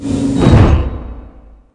miningdronesreturn.ogg